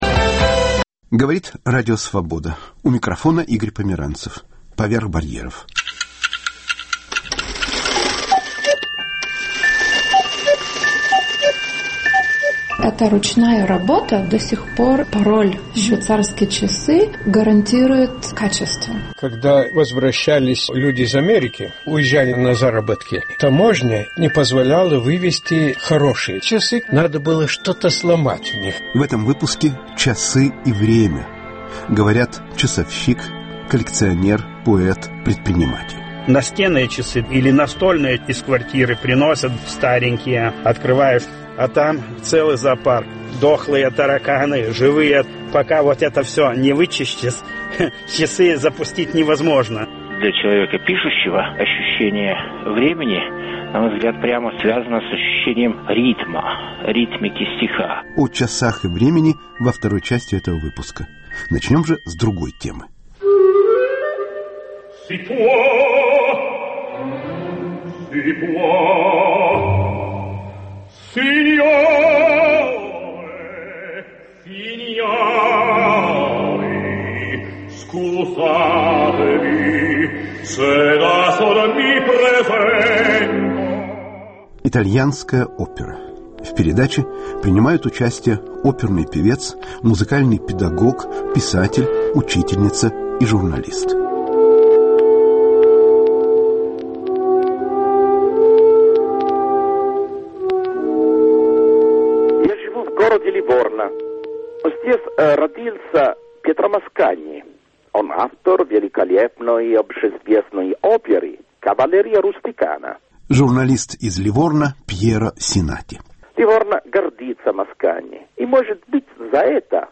Итальянская опера. Передача с участием оперного певца Зураба Соткилавы*** Часы и время.